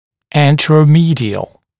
anteromedial.mp3